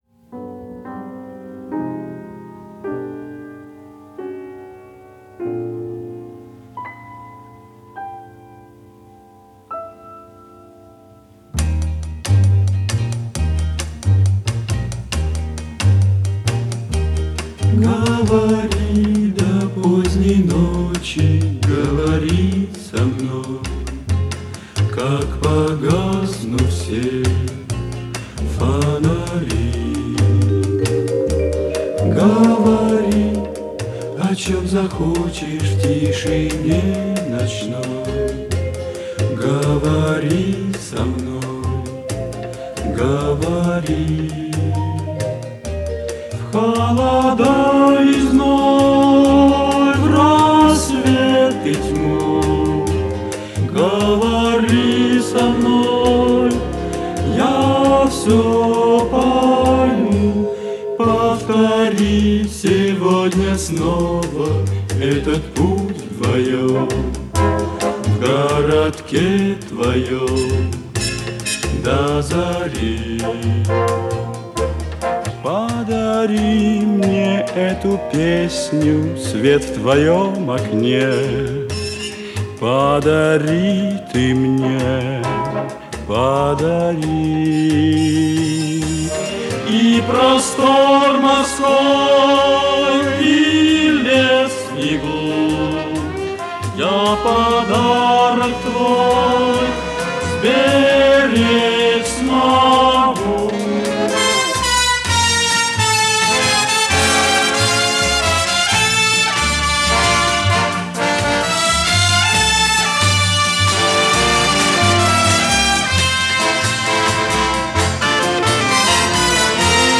в хорошем качестве
Да, качество хорошее.